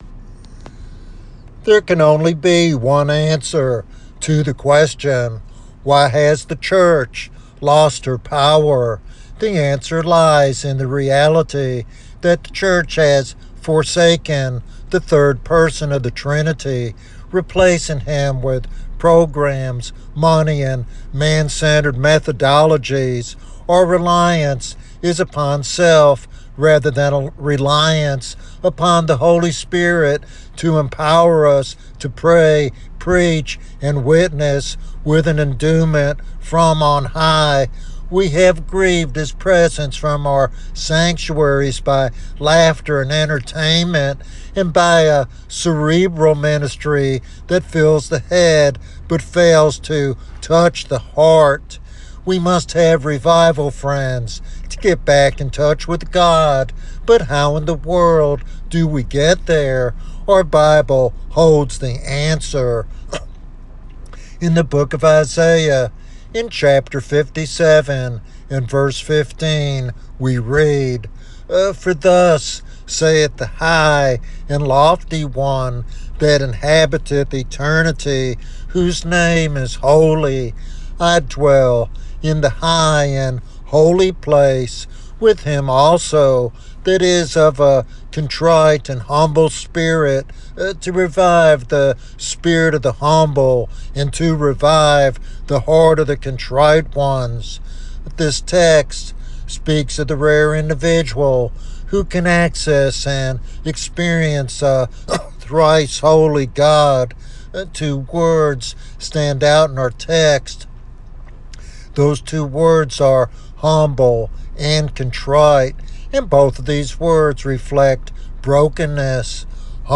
This sermon challenges listeners to embrace brokenness as the pathway to experiencing God's revival power.